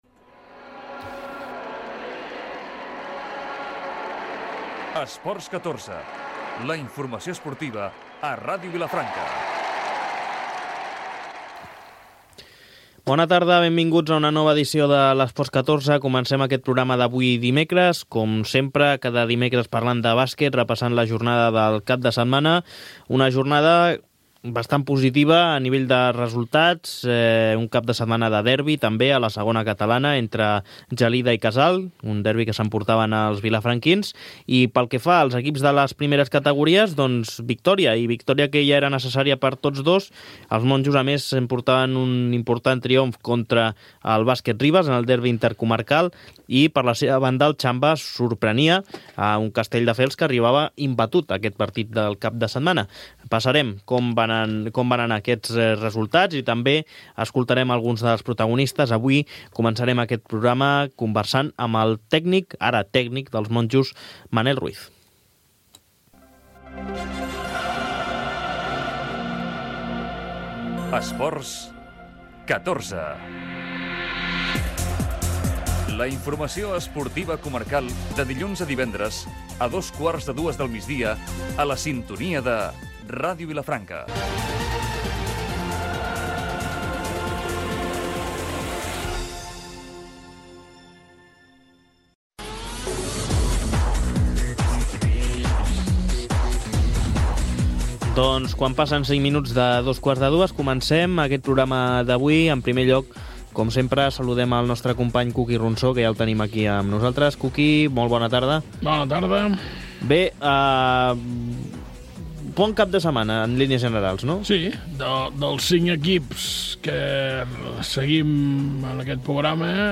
Careta del programa, presentació, informació de la jornada de bàsquet del cap de setmana, indicatiu, hora, valoració dels resultats dels equips de bàsquet locals i informació dels partits celebrats
Esportiu